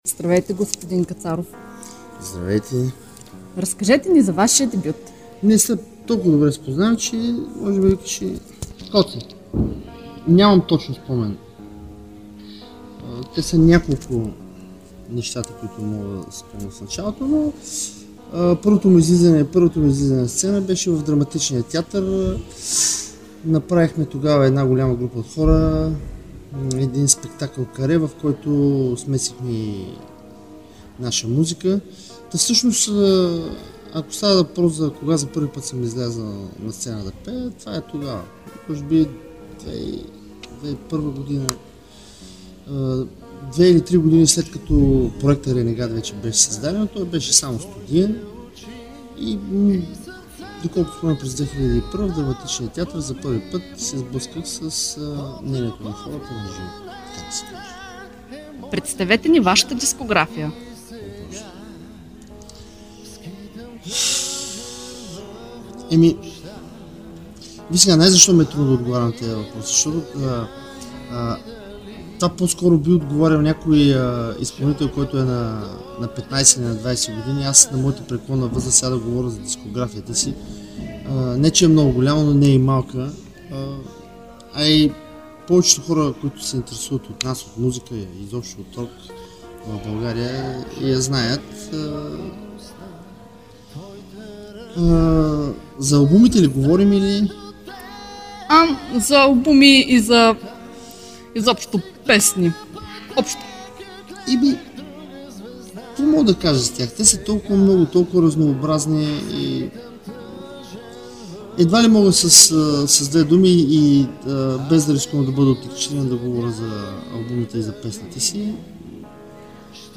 renegat-intervu.mp3